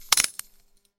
Branch Snap
A dry wooden branch snapping underfoot with sharp crack and splintering fibers
branch-snap.mp3